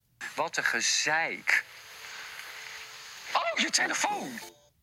Instrumentele